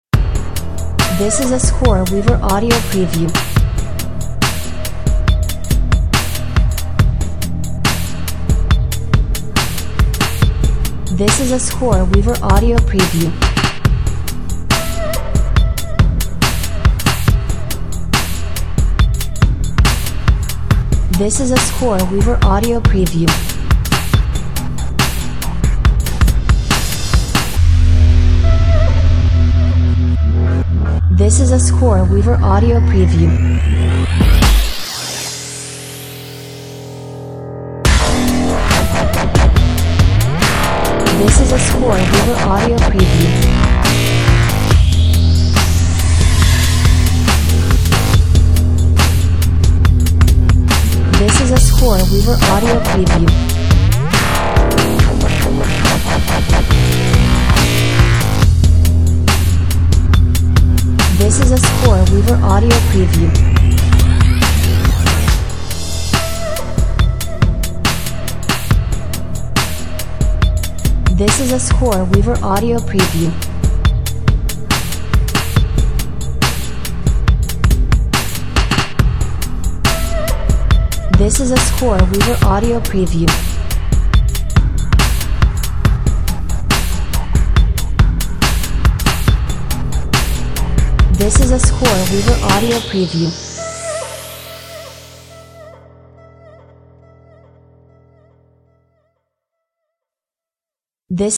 Calms down later on like nothing ever happened.